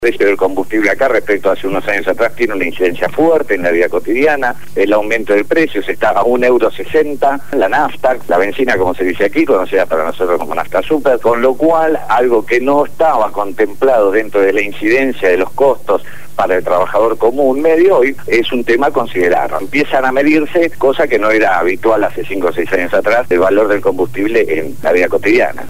habló desde Italia y dio un completo informe de la situación crítica que vive el país europeo.